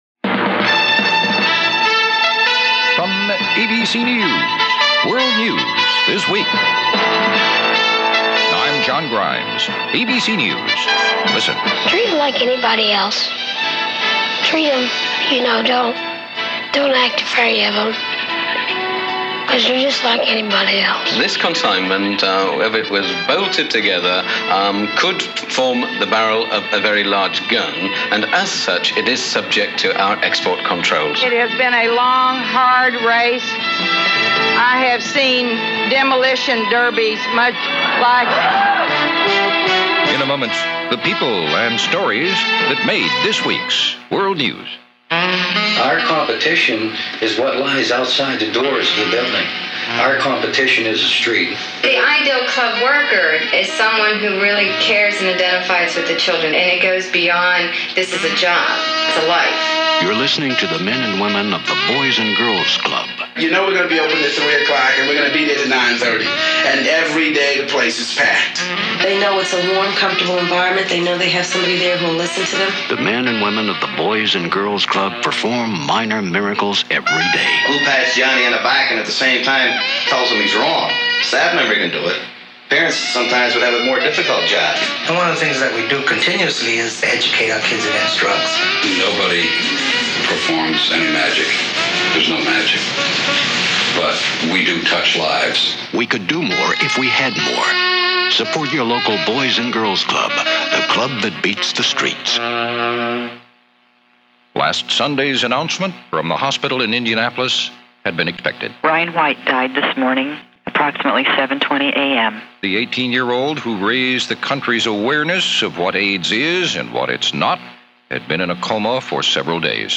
And so went the news for this week in 1990, along with a vast collection of other stuff that put 1990 on the map, as reported by ABC Radio and their weekly, ABC World This Week for April 15, 1990.